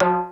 DMX TOM 3.wav